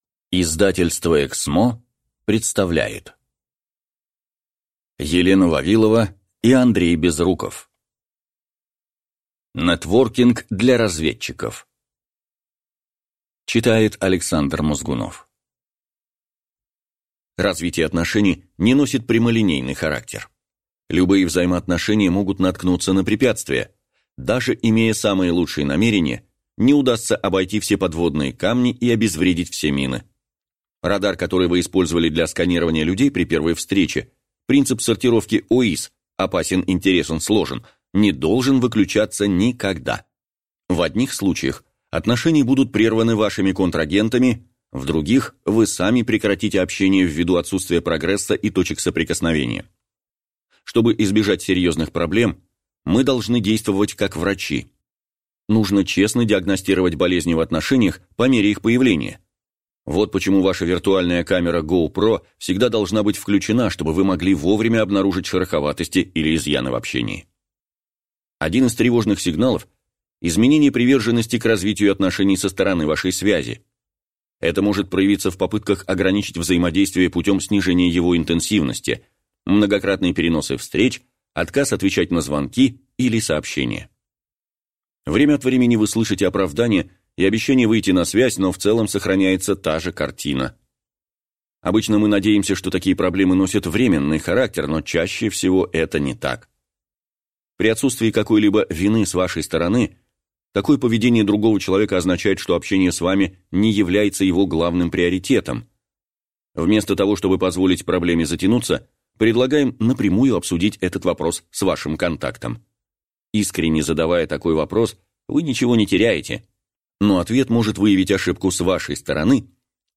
Аудиокнига Нетворкинг для разведчиков. Как извлечь пользу из любого знакомства | Библиотека аудиокниг